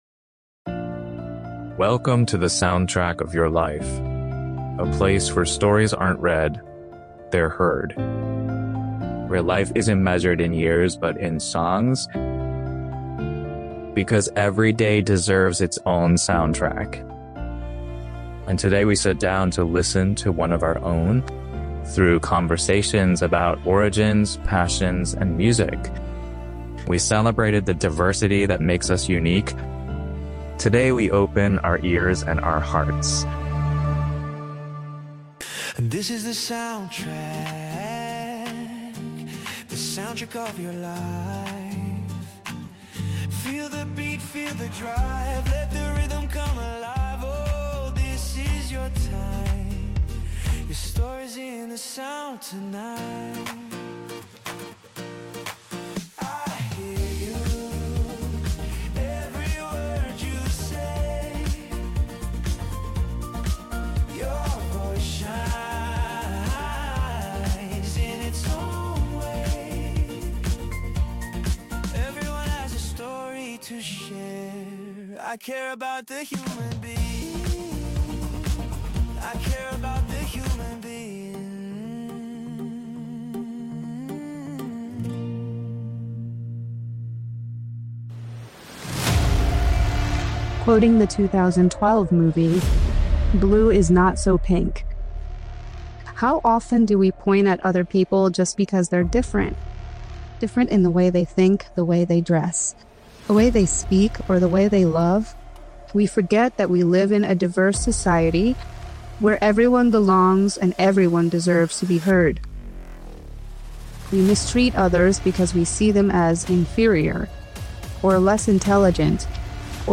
An interview that takes us to know the country of Myanmar through the words of one of its favorite daughters.&#160